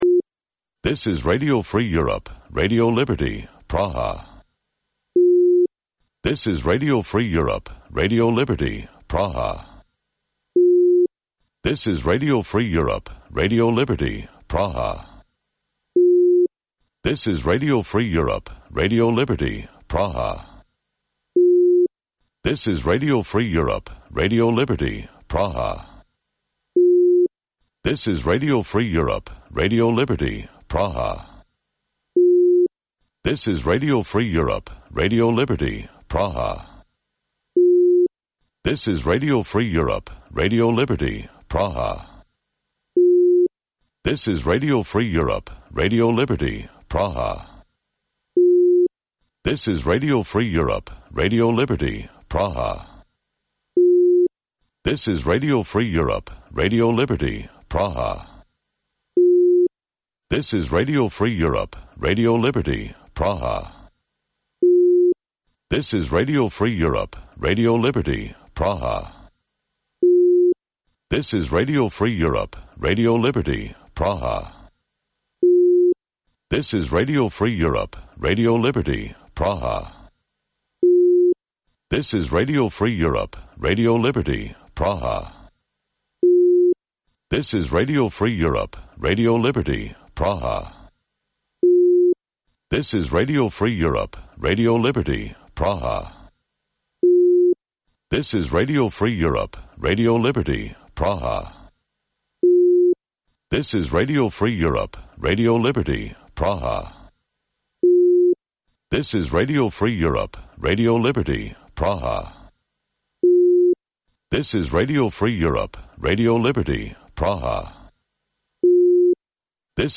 Утринска програма на Радио Слободна Европа од Студиото во Скопје. Во 15 минутната програма од понеделник до петок можете да слушате вести и прилози од земјата, регионот и светот. Во голем дел емисијата е посветена на локални настани, случувања и приказни од секојдневниот живот на граѓаните во Македонија.